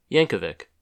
Alfred Matthew "Weird Al" Yankovic (/ˈjæŋkəvɪk/
En-us-Yankovic.oga.mp3